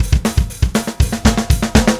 Power Pop Punk Drums 01 Fill C.wav